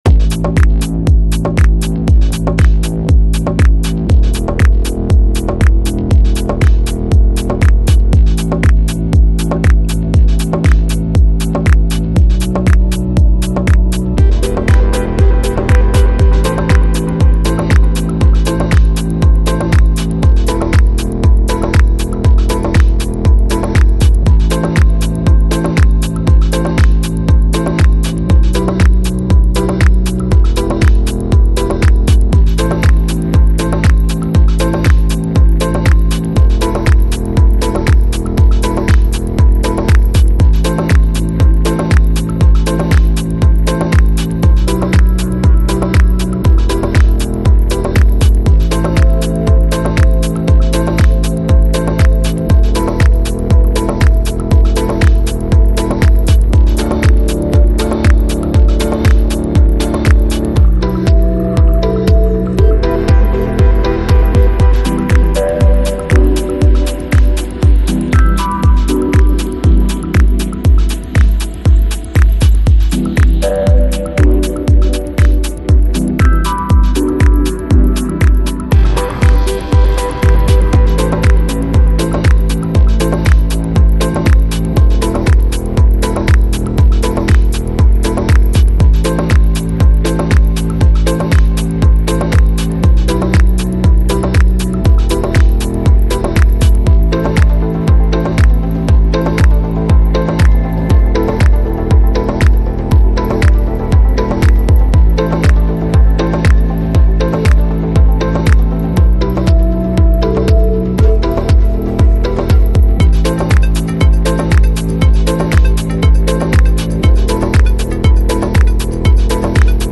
Жанр: Chillout, Lounge, Ambient, Downtempo, House